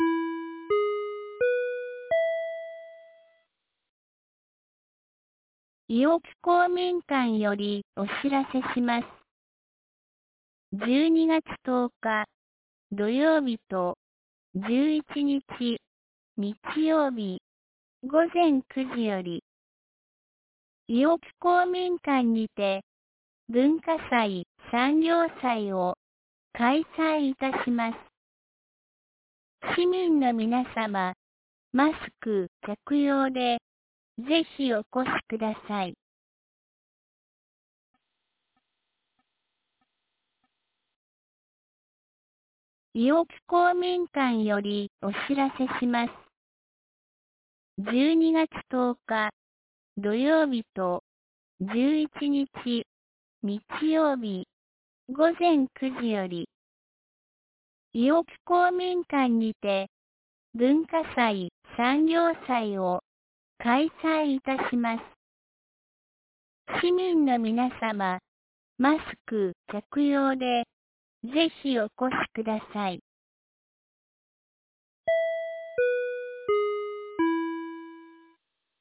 2022年12月09日 17時11分に、安芸市より全地区へ放送がありました。